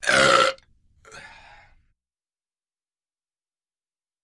Отец – Ещё разок